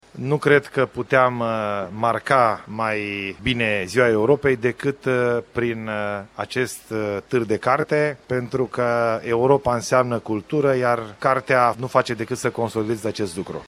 La deschiderea târgului Gaudeamus Radio România a fost prezent și primarul Oradiei, Ilie Bolojan: